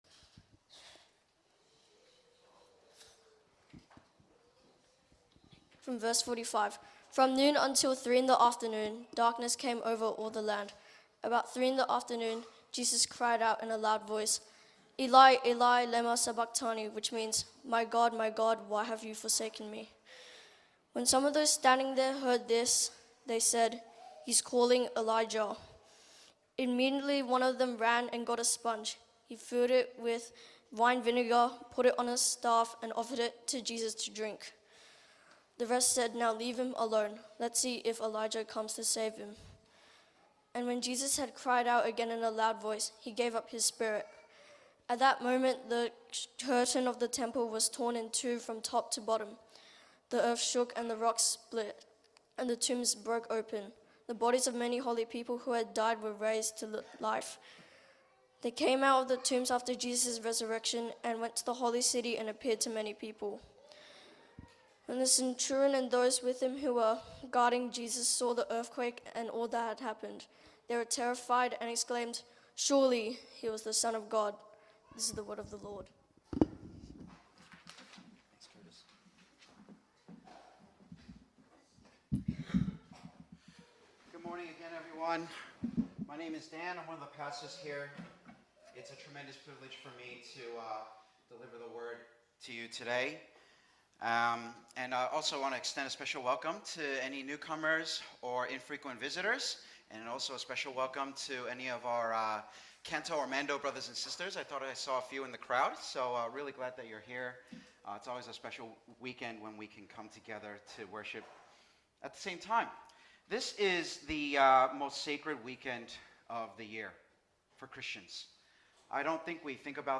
English-Good-Friday-April-18th.mp3